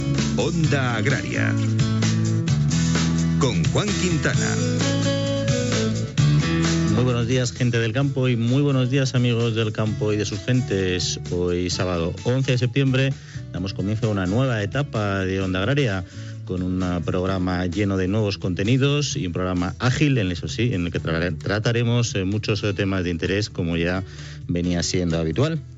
Careta del programa, data, inici de la nova etapa del programa